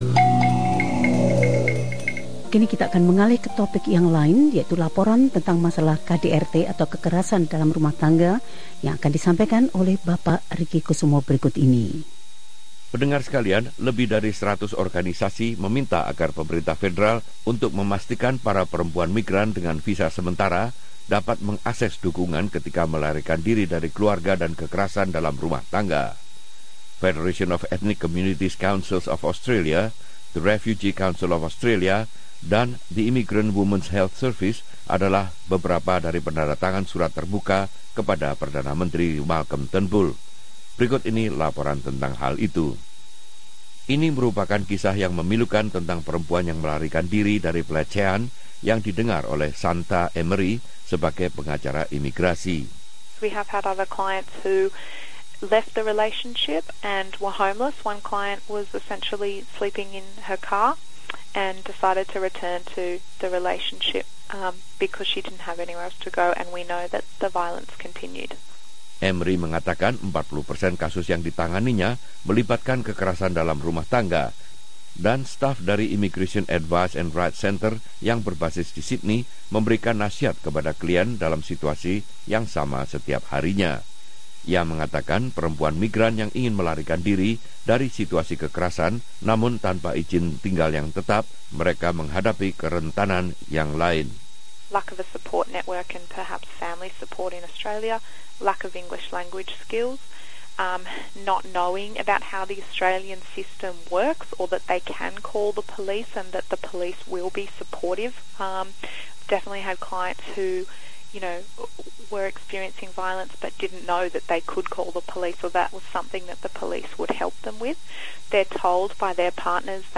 Laporan ini membahas isu-isu tersebut.